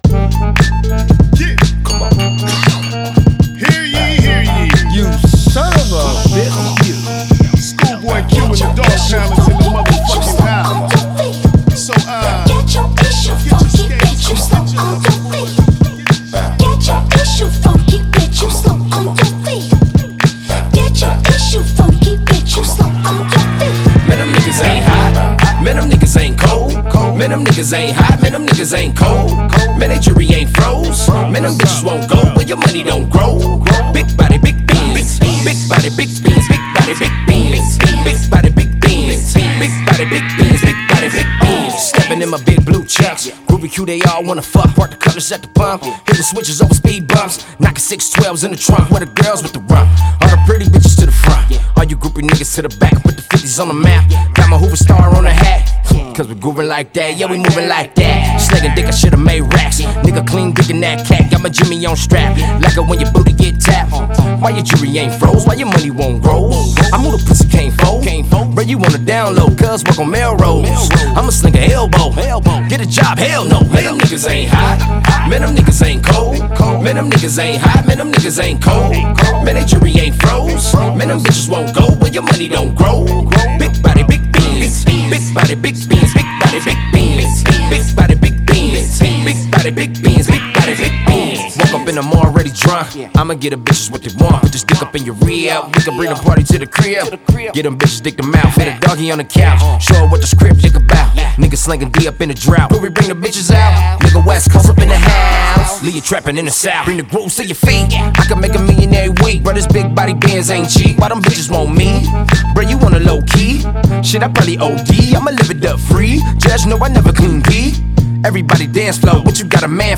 4. ALTERNATIVE